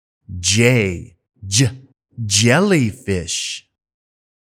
単語の読み方・発音